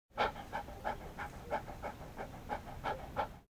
dog_panting.ogg